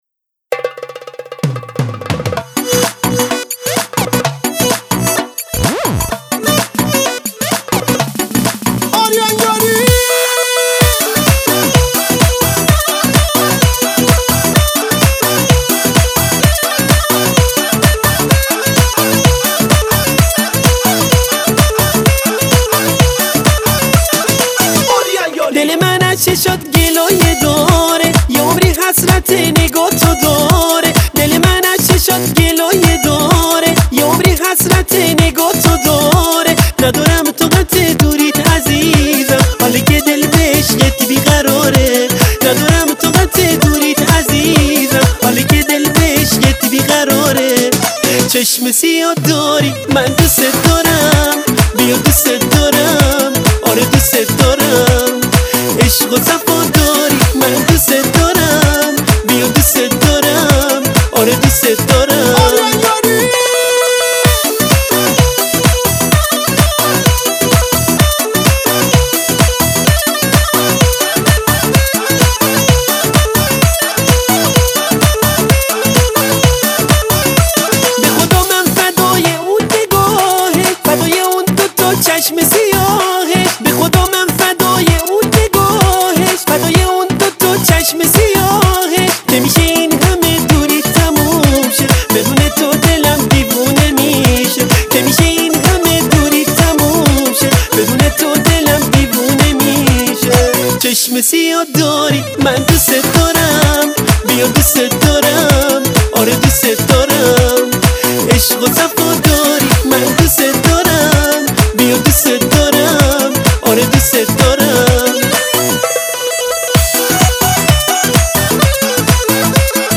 آهنگ مخصوص مراسم عروسی